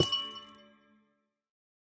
Minecraft Version Minecraft Version 1.21.5 Latest Release | Latest Snapshot 1.21.5 / assets / minecraft / sounds / block / amethyst_cluster / place3.ogg Compare With Compare With Latest Release | Latest Snapshot